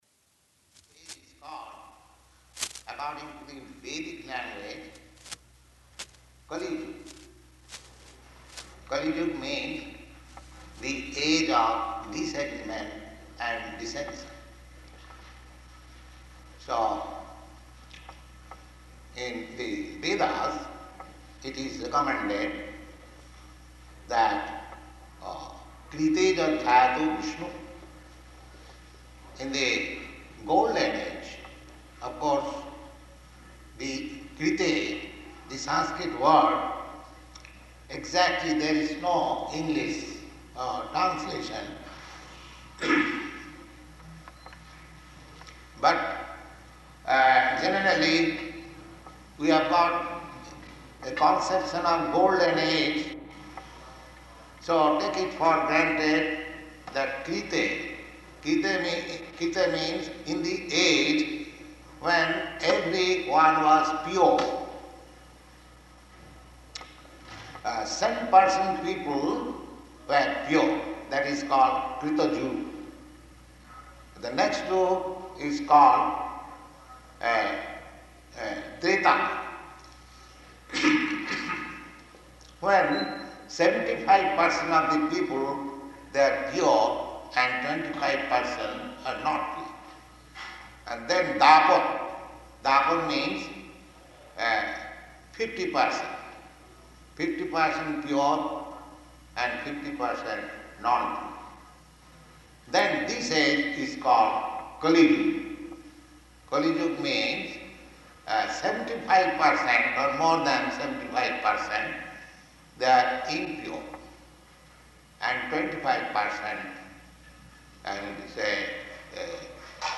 Lecture Engagement and Prasāda Distribution --:-- --:-- Type: Lectures and Addresses Dated: April 26th 1969 Location: Boston Audio file: 690426LE-BOSTON.mp3 Prabhupāda: This age is called, according to the Vedic language, Kali.